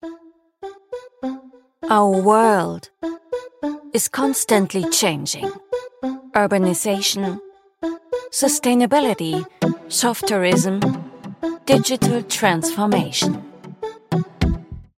Corporate Videos
🎧 Voice style: Warm · Modern · Confident · Engaging · Trustworthy · Natural
💬 Languages: German (native) | English (neutral European / British)